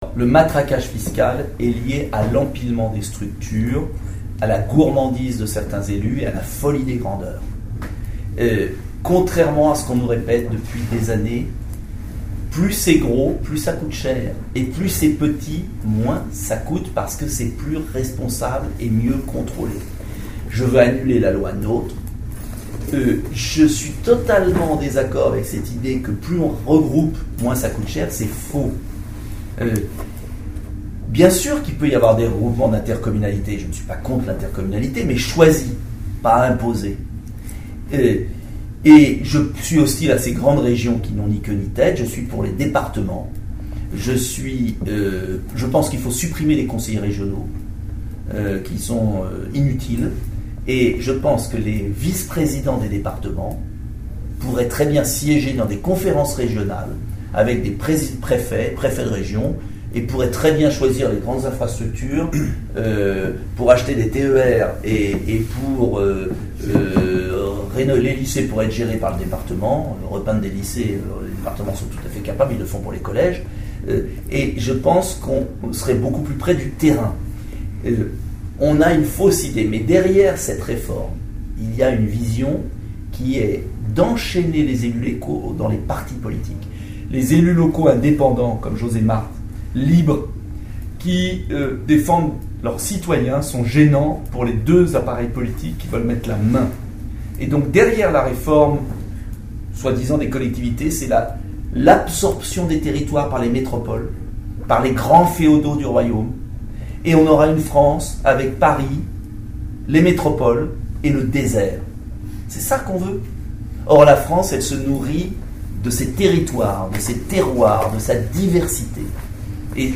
La conférence de presse
A son arrivée à l’aéroport de Tarbes-Lourdes-Pyrénées, Nicolas Dupont-Aignan a tenu une conférence de presse dans une salle de l’aérogare d’affaires.